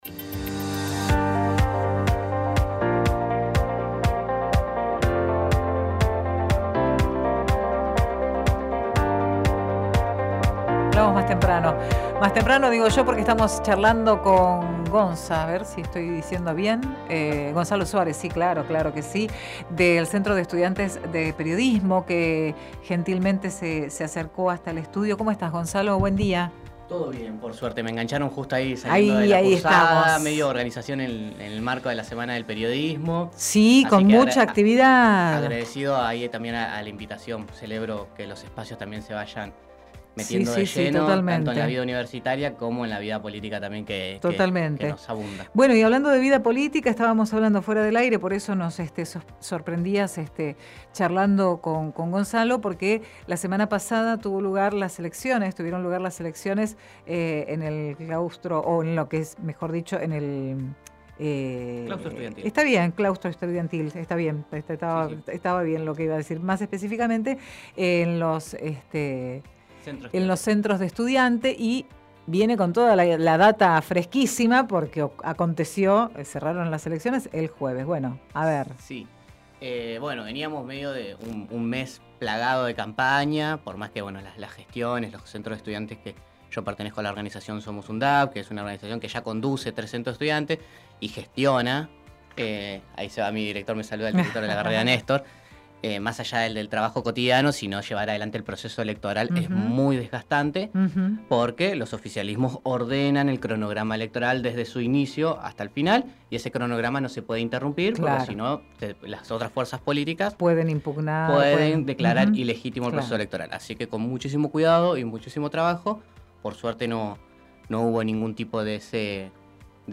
Compartimos con ustedes la entrevista realizada en "Qué te importa?!"